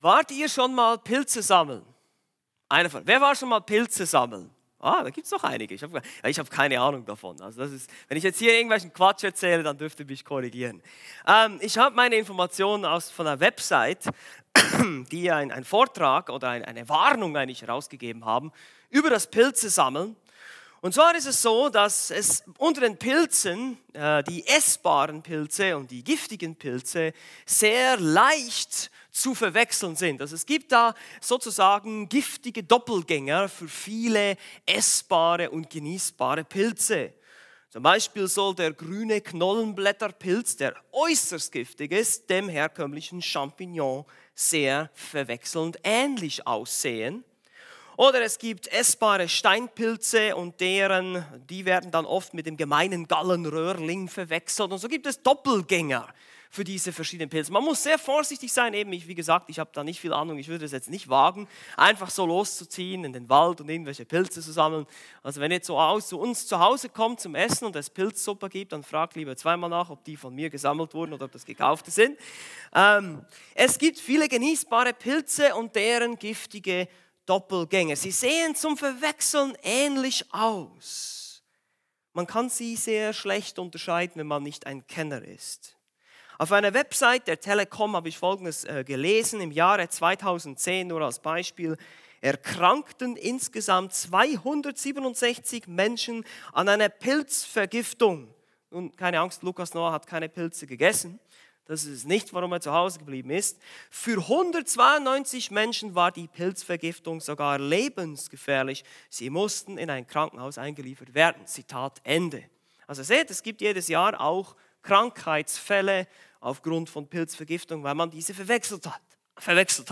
A predigt from the serie "Weitere Predigten."